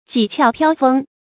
濟竅飄風 注音： ㄐㄧˋ ㄑㄧㄠˋ ㄆㄧㄠ ㄈㄥ 讀音讀法： 意思解釋： 語出《莊子·齊物論》：「飄風則大和，厲風濟則眾竅為虛。」